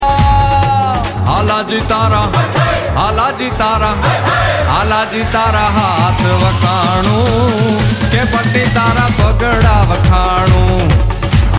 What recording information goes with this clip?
Hindu Temple in The UK sound effects free download